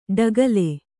♪ ḍagale